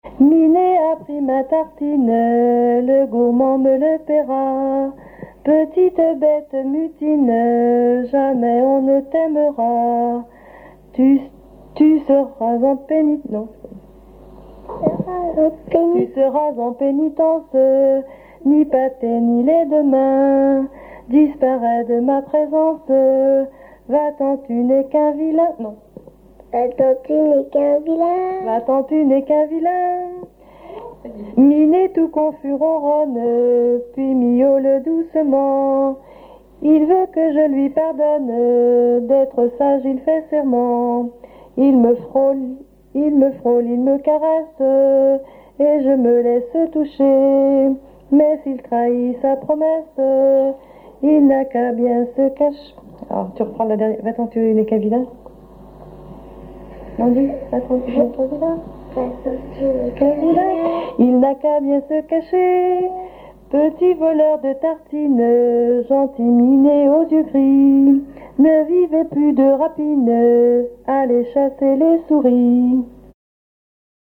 Saint-Jean-de-Monts
Pièce musicale inédite